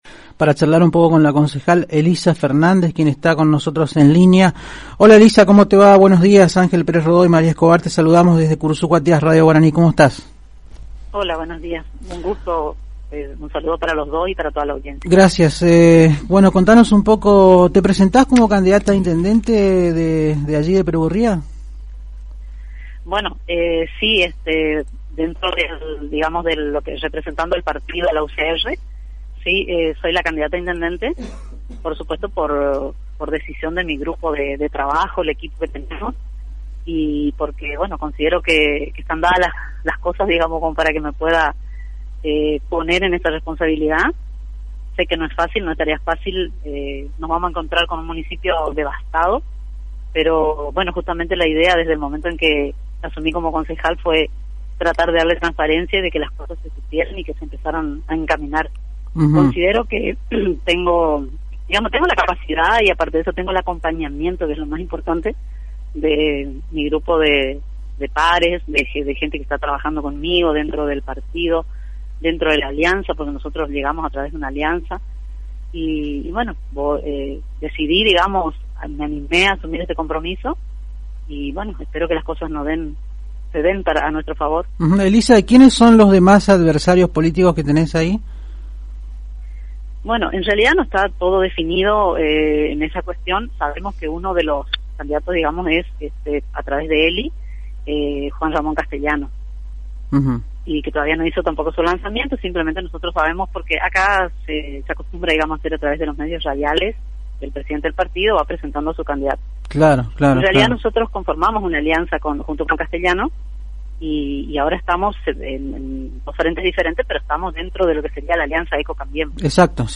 (Audio) Así lo confirmó la concejal de Perugorría, Elisa Fernández cuyo nombre es el que más suena dentro del Radicalismo perugorriano como candidata a Intendente de la mencionada localidad.
"Representando al Partido Radical soy la candidata a Intendente por decisión de mi grupo de trabajo y están dadas las cosas para ésta responsabilidad, nos vamos a encontrar con un Municipio devastado", pronosticó la concejal en diálogo con la AM 970 Radio Guarani.